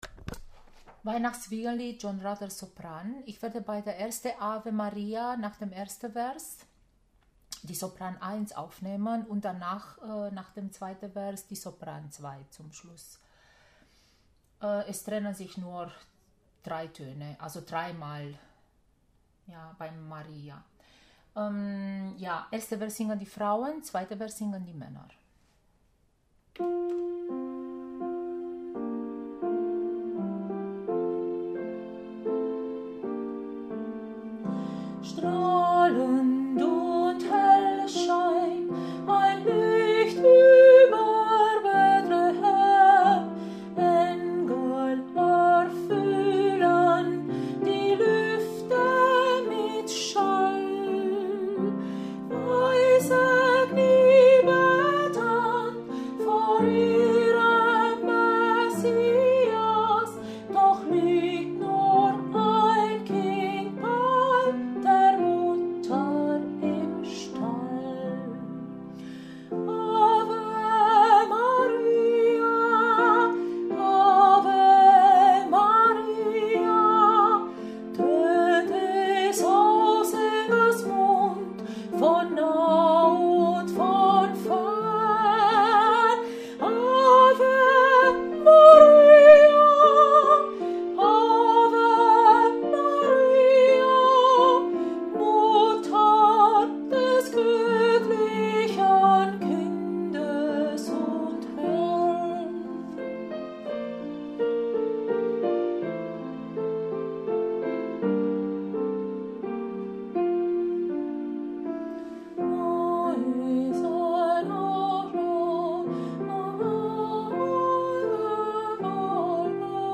John Rutter Weihn.-Wiegenlied Sopran